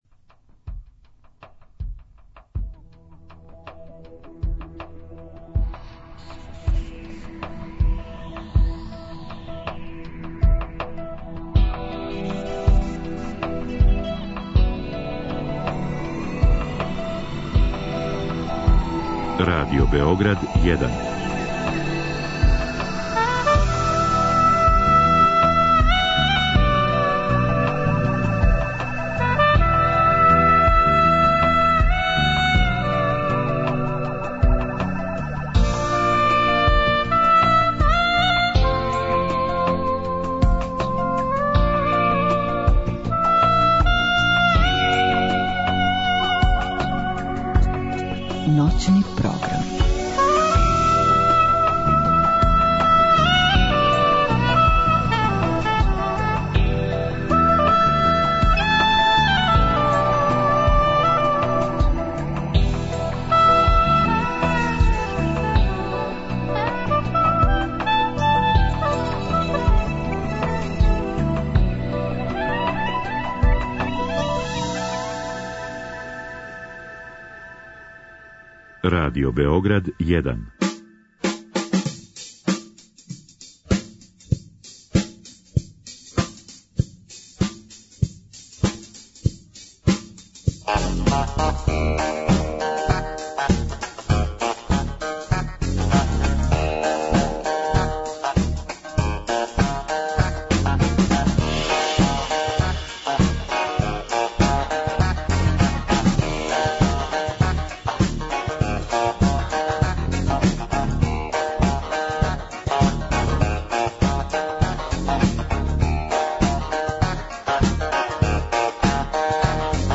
Радио Београд 1, 00.05